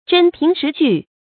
真凭实据 zhēn píng shí jù
真凭实据发音
成语注音ㄓㄣ ㄆㄧㄥˊ ㄕㄧˊ ㄐㄨˋ